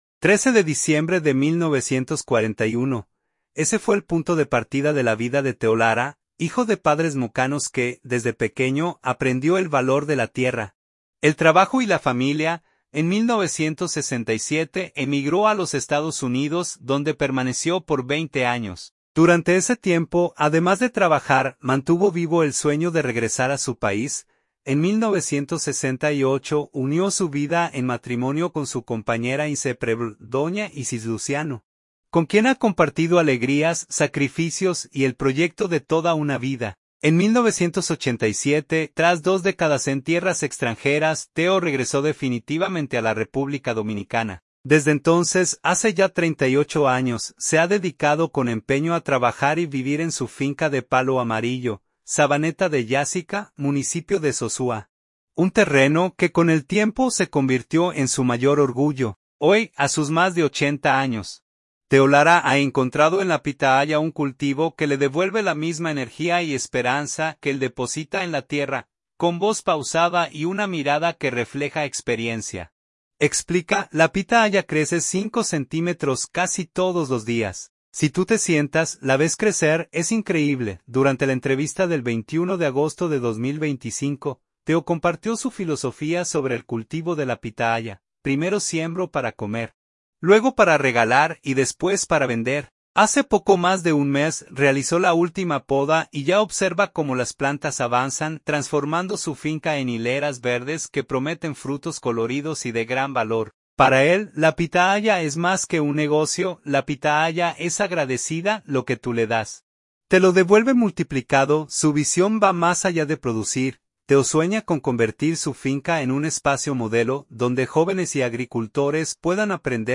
Con voz pausada y una mirada que refleja experiencia, explica: